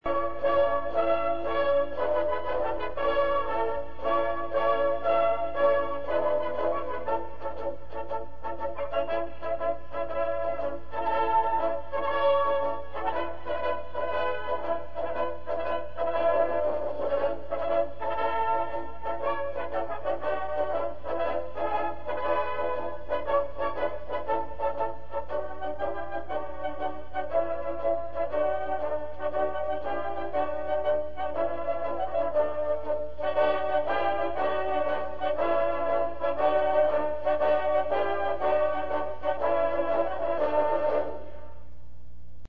South Alberta Regimental March:   "